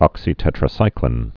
(ŏksē-tĕtrə-sīklĭn, -klēn)